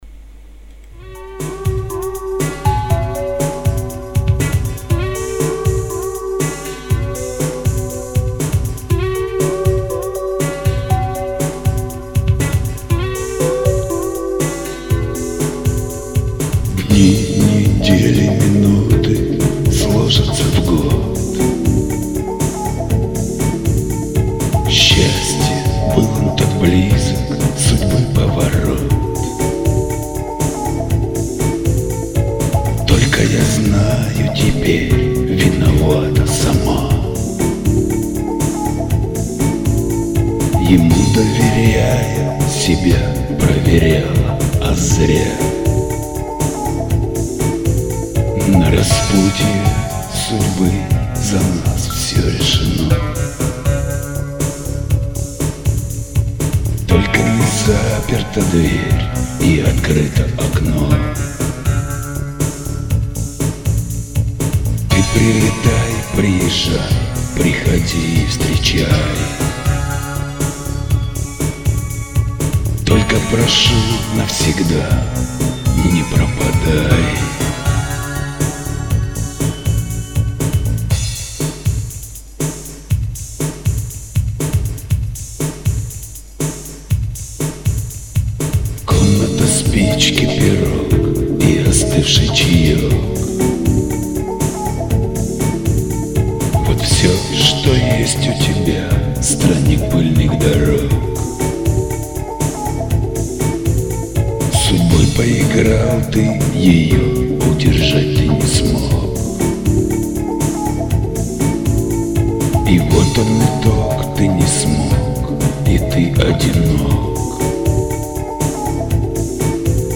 «Авторска композиция, баллада»